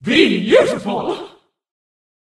bea_mon_kill_vo_02.ogg